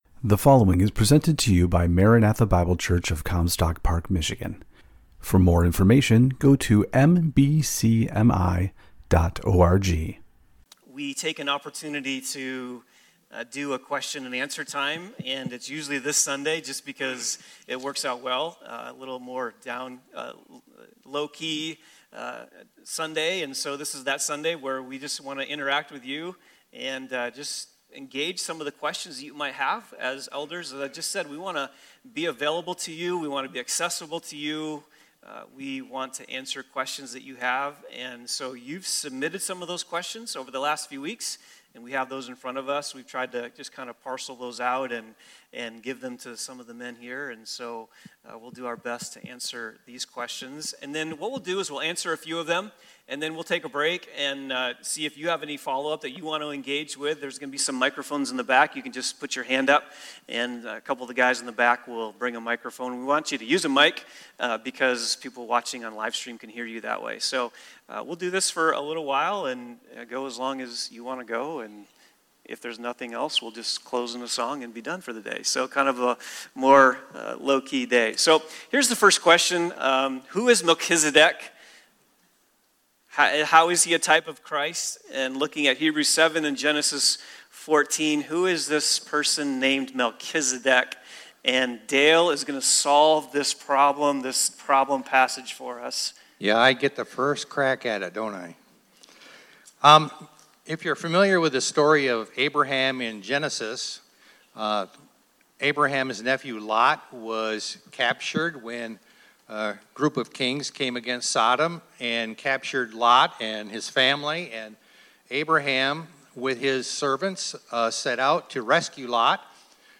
Elder Q & A